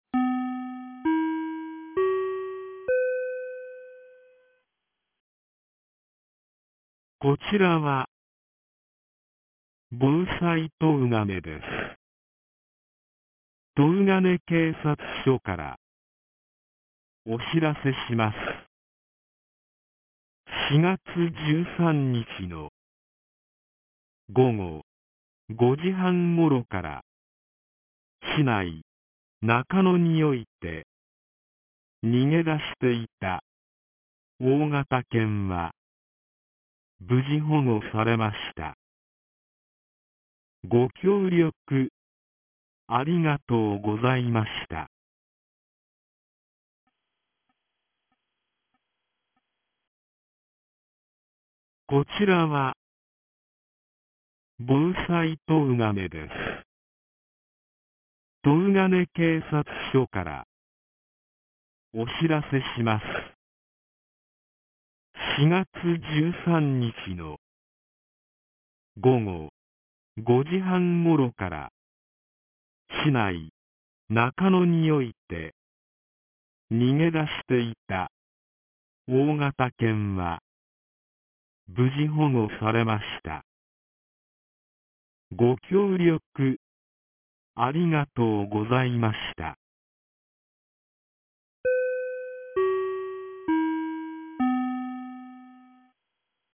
2025年04月15日 17時46分に、東金市より防災行政無線の放送を行いました。